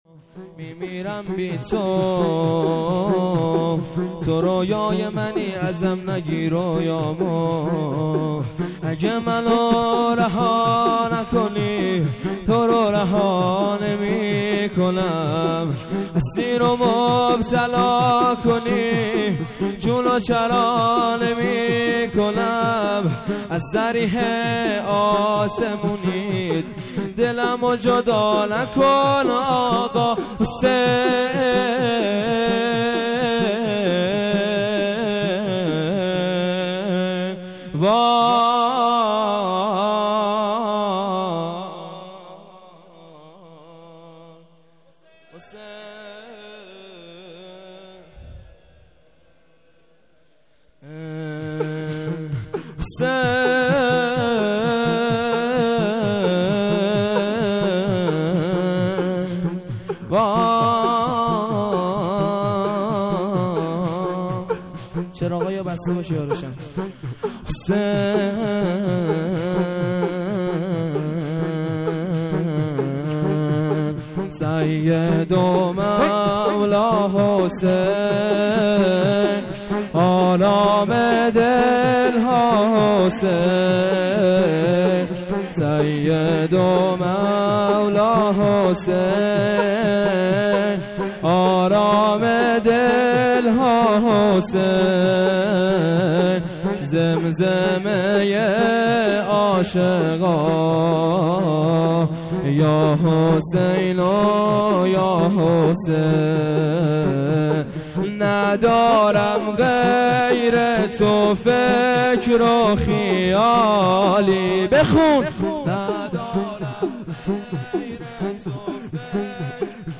می میرم بی تو | شور
یادمان فاطمیه